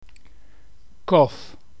Correction-Kof.mp3